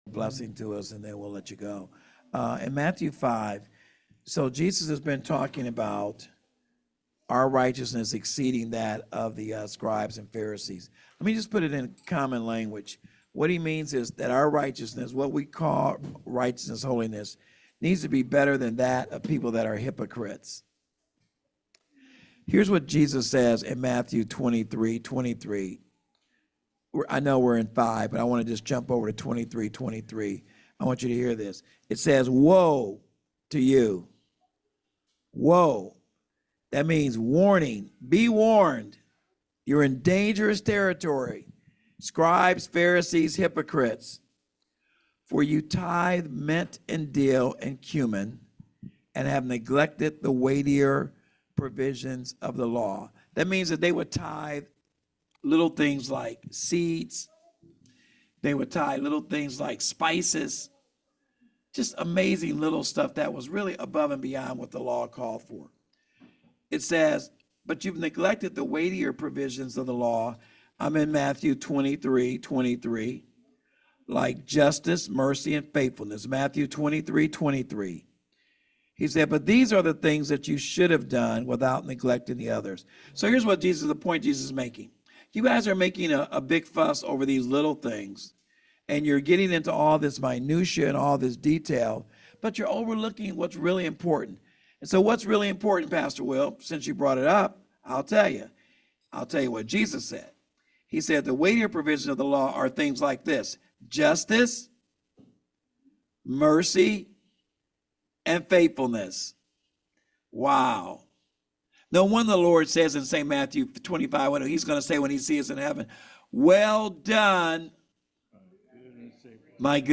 Sermon Summary